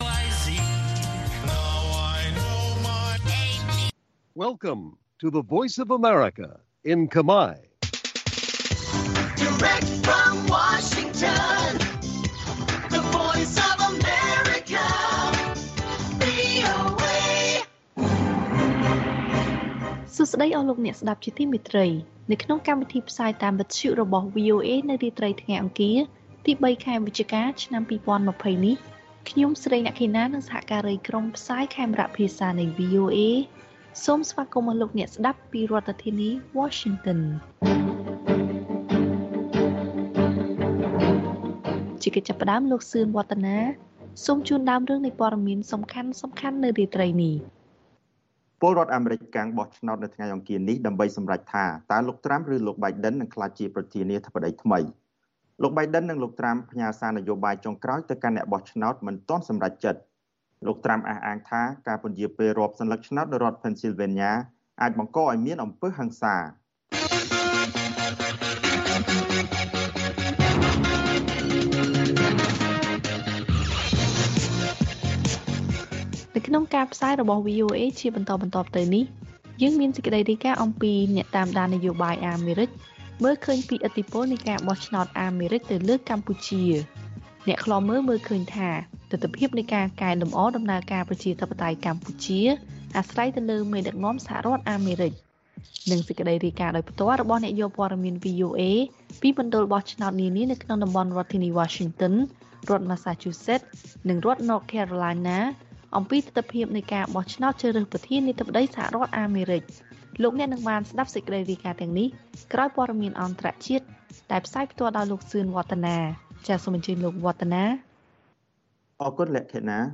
ព័ត៌មានអន្តរជាតិវិញមានដូចជា បទសន្ទនារវាងអ្នកយកព័ត៌មាន VOA ខ្មែរ អំពីស្ថានភាពបោះឆ្នោតជ្រើសរើសប្រធានាធិបតីសហរដ្ឋអាមេរិកថ្មីនៅតាមការិយាល័យបោះឆ្នោត។ មន្ត្រីពង្រឹងច្បាប់ធានាអះអាងចំពោះអ្នកបោះឆ្នោតថា ការបោះឆ្នោតមានសុវត្ថិភាពនិងសន្តិសុខល្អ។